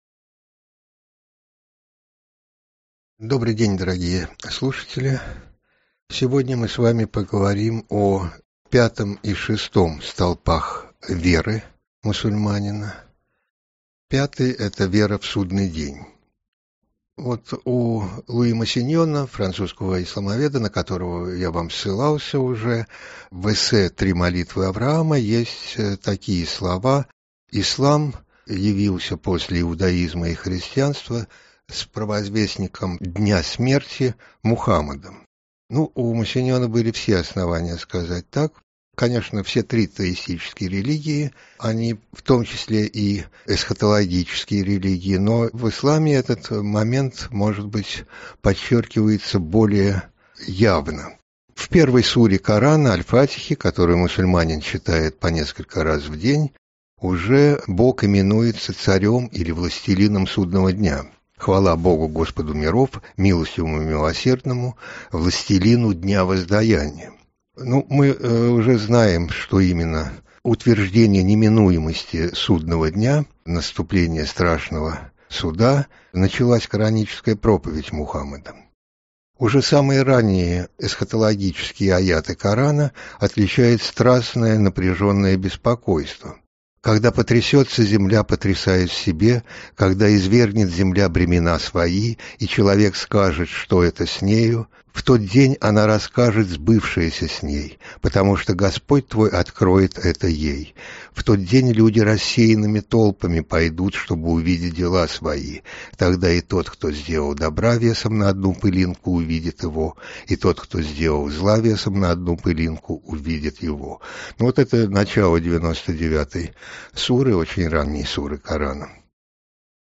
Аудиокнига Эсхатология.